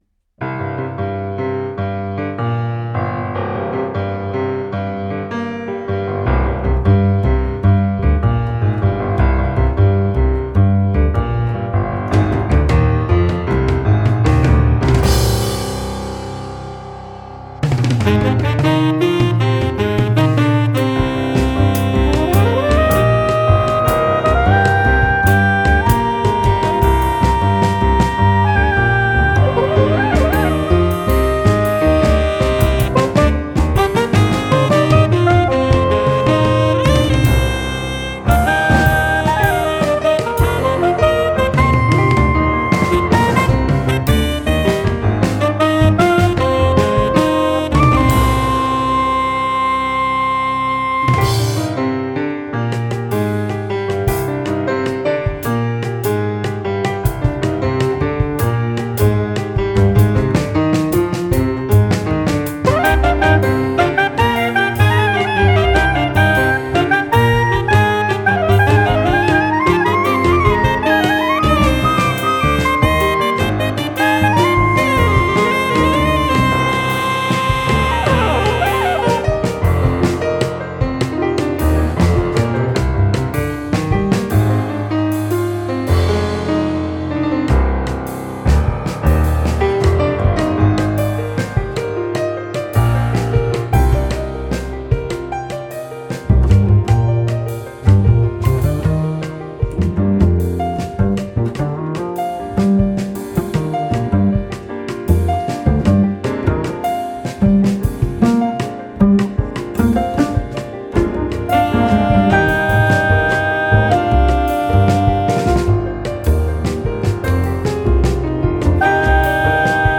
sopranosaxophone
tenorsaxophone
bassguitar, doublebass
drums
electric piano